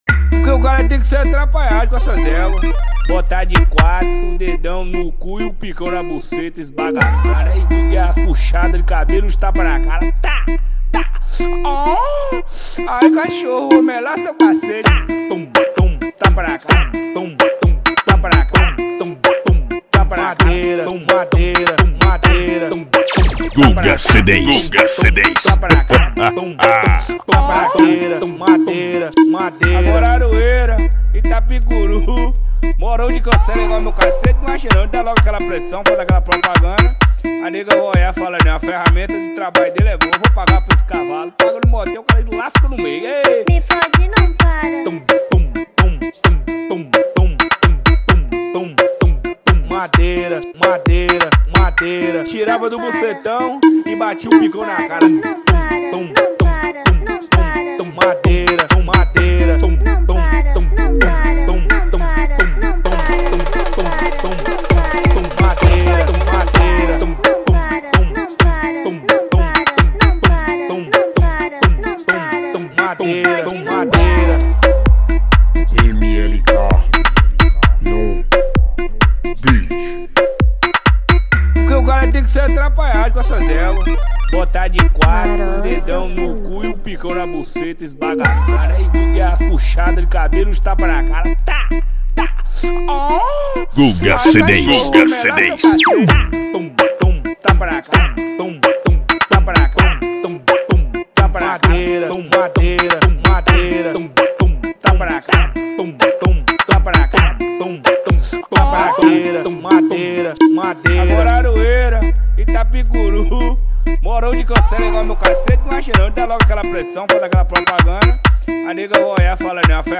2024-06-16 19:55:47 Gênero: MPB Views